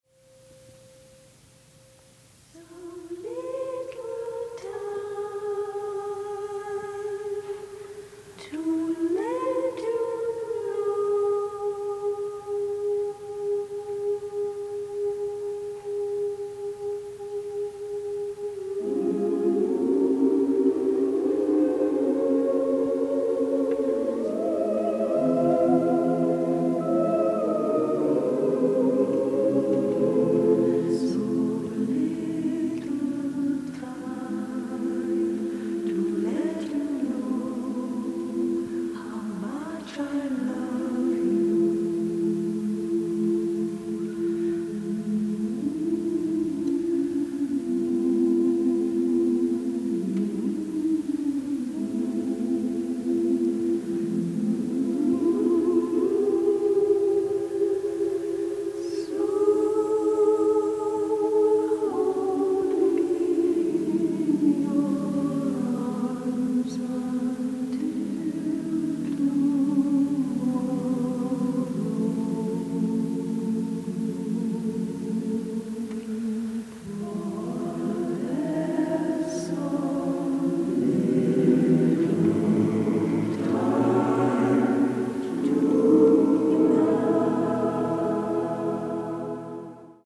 robust orchestral score